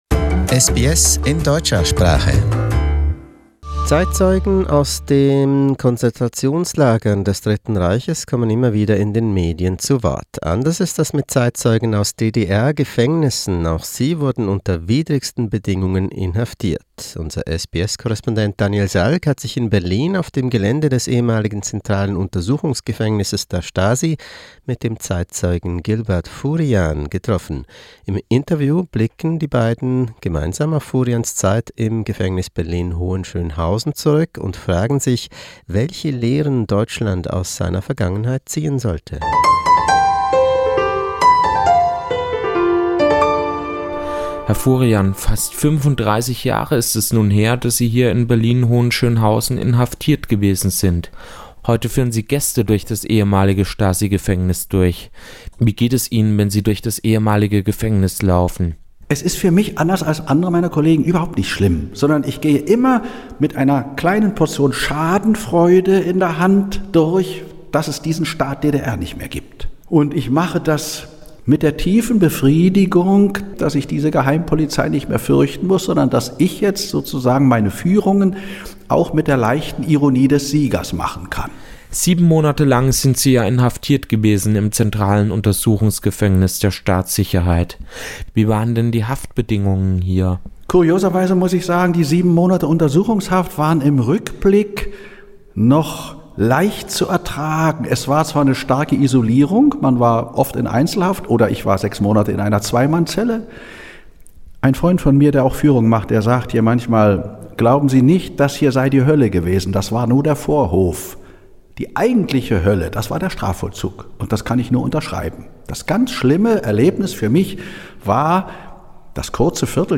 Stasi Prison: Interview with a former victim